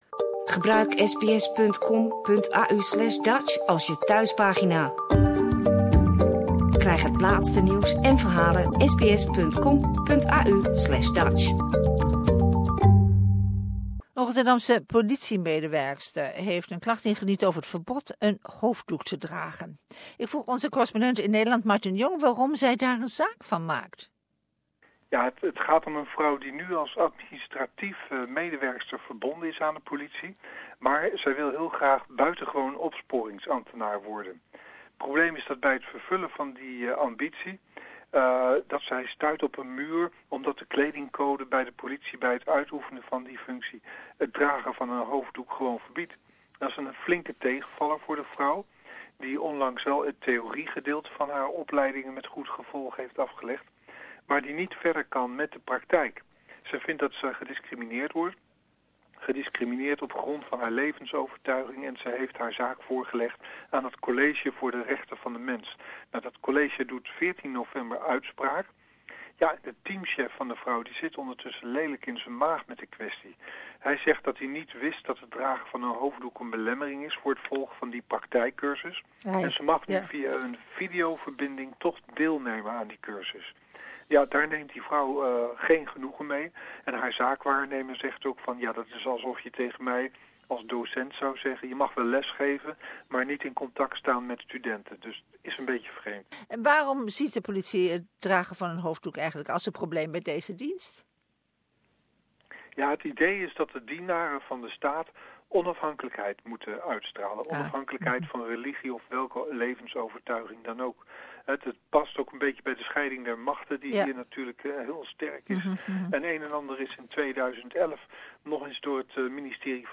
A woman working at the Amsterdam police service wants to join another area of the service but this service does not allow the wearing a religious symbols like head scarves. Dutch correspondent